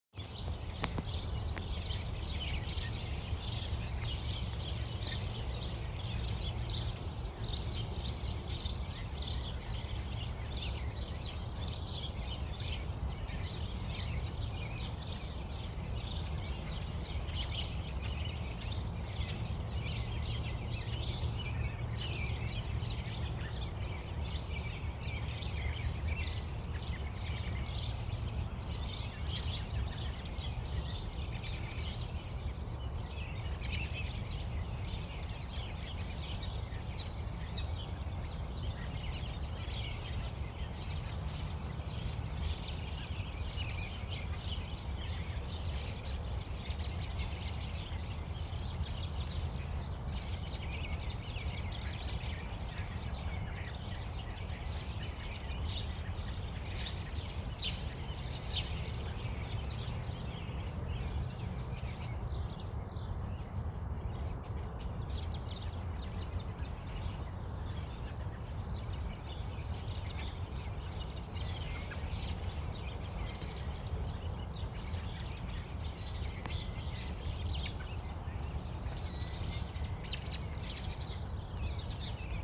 I captured a little sound slice while watching them:
This audio was before more nuthatches came around. It was such a delight to watch the birds flitting from tree to tree, feasting on the winter leavings and preening themselves.
1-17-23 winter birds.mp3